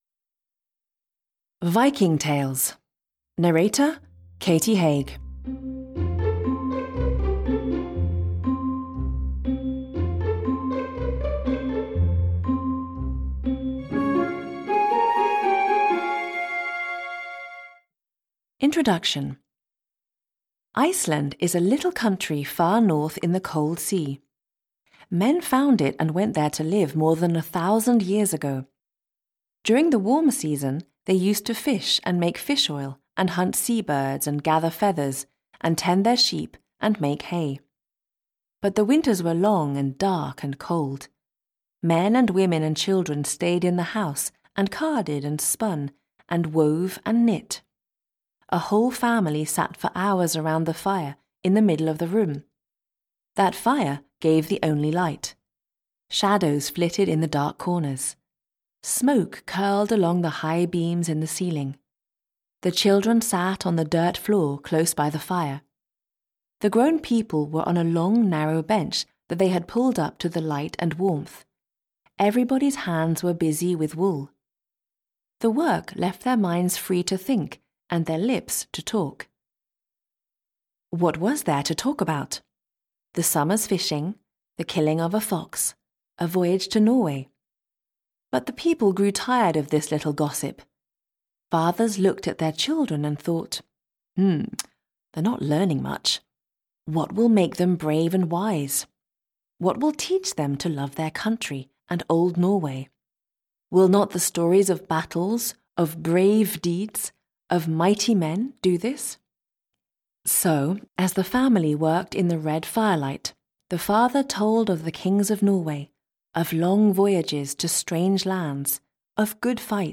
Viking Tales and Legends (EN) audiokniha
Ukázka z knihy